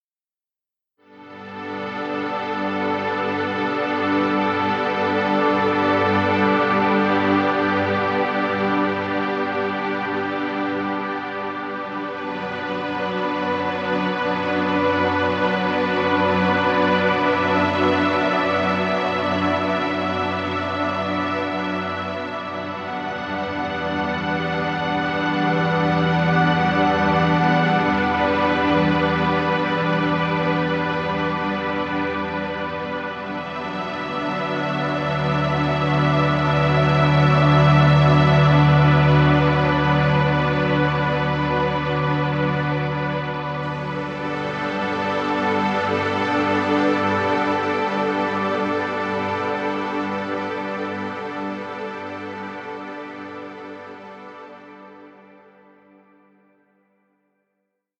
Relaxing music. Background music Royalty Free.